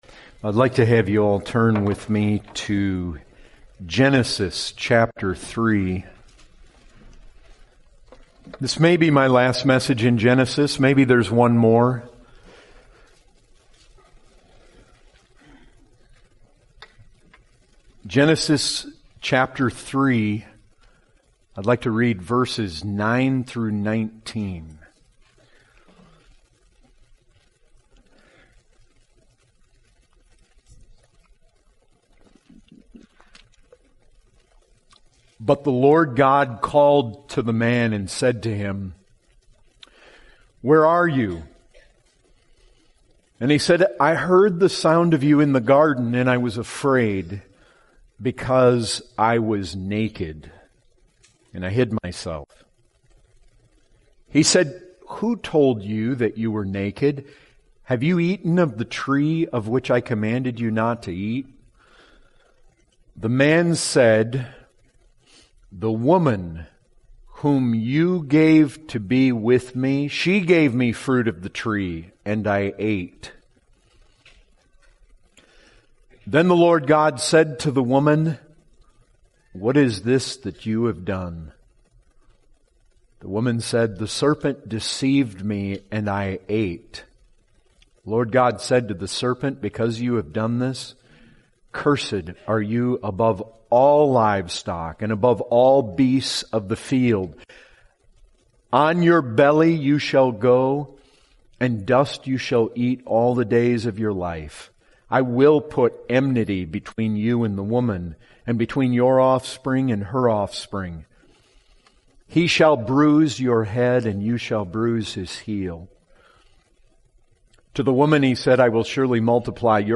2019 Category: Full Sermons Topic